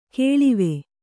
♪ kēḷive